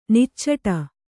♪ niccaṭa